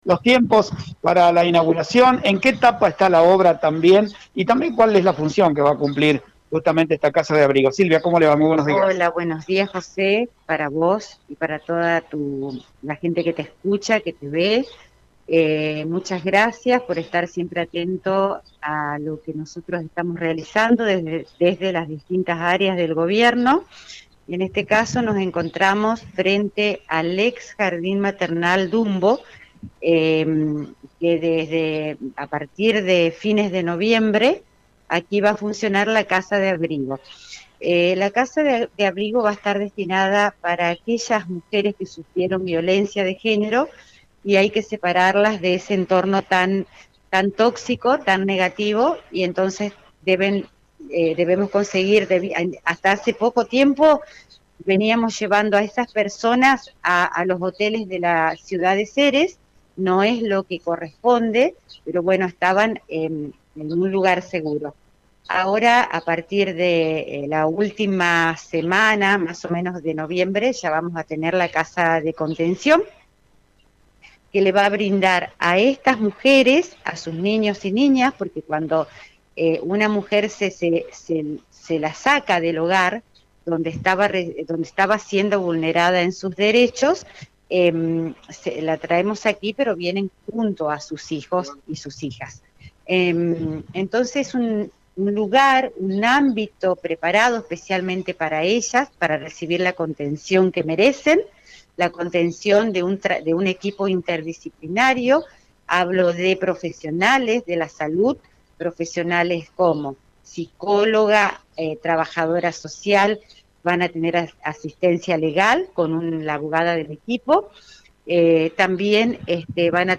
En FM CERES 98.7 Mhz. Hablamos con la Secretaria de Desarrollo Humano, Silvia García quien nos contó sobre los detalles del funcionamiento de la Casa de Abrigo.